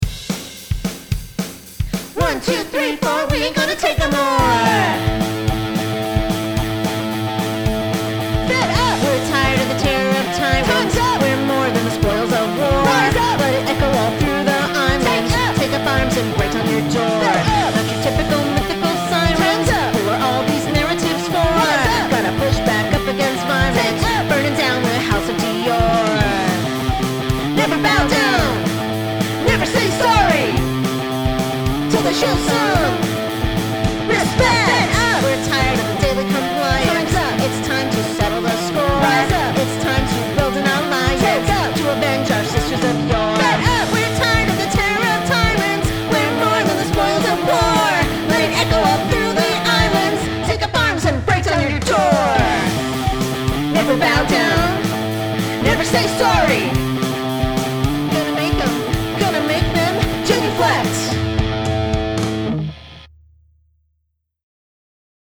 Liars & Believers transforms ancient, divine justice into contemporary, thrashing vengeance – in a new theatrical adaptation with driving text, kinetic physicality, and a Riot-Grrrl punk band.
These are the first demo recordings